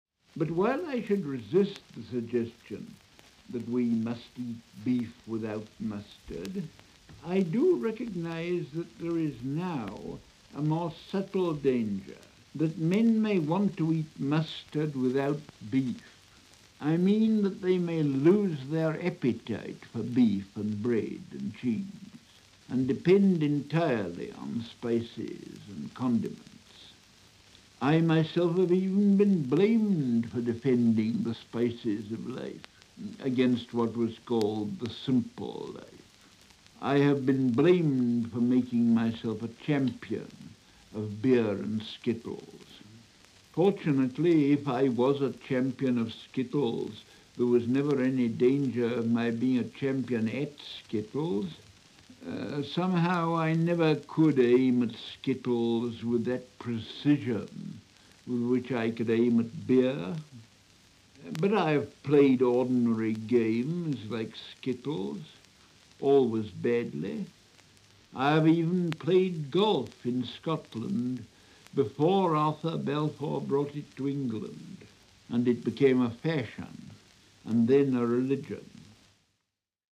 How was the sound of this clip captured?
The Spice of Life from the BBC Sound Archive